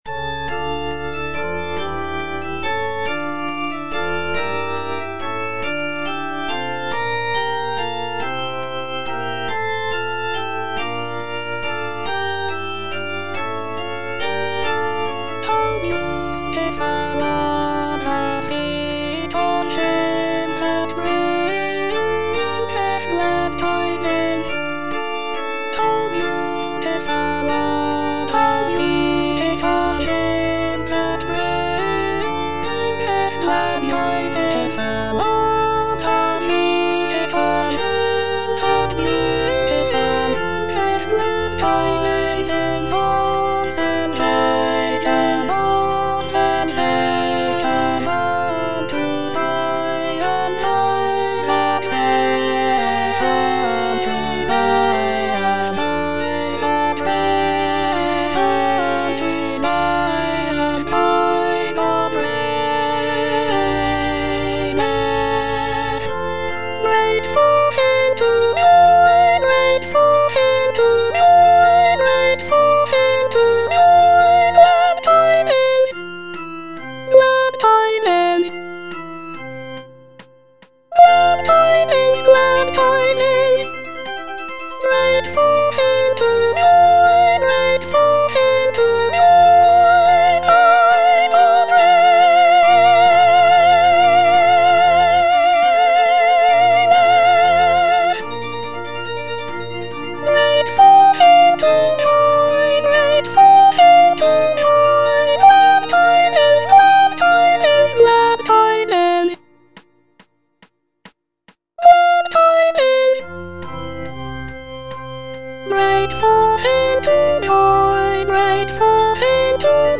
G.F. HÄNDEL - HOW BEAUTIFUL ARE THE FEET OF HIM FROM "MESSIAH" (DUBLIN 1742 VERSION) Soprano (Voice with metronome, organ) Ads stop: